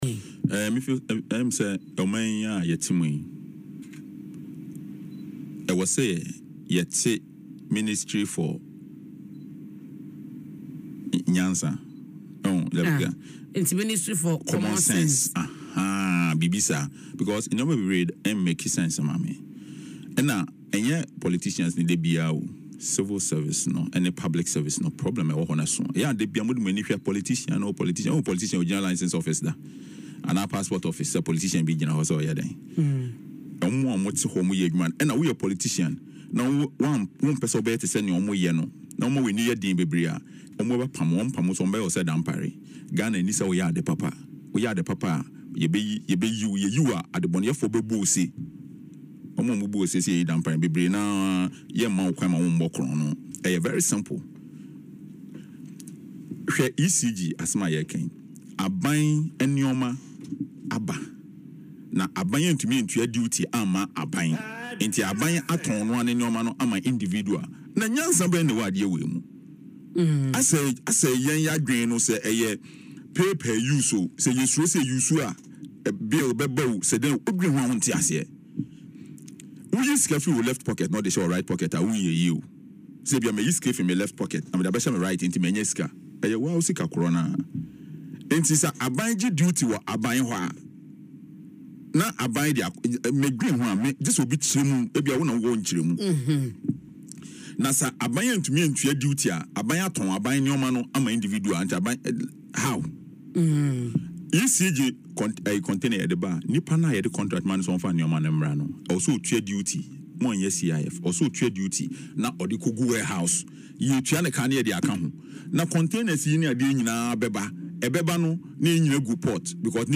He made this call during an interview on Adom FM’s Dwaso Nsem while reacting to the controversy surrounding the missing Electricity Company of Ghana (ECG) containers at the Tema Port.